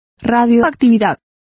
Complete with pronunciation of the translation of radioactivity.